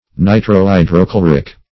Nitrohydrochloric \Ni`tro*hy`dro*chlo"ric\, a. [Nitro- +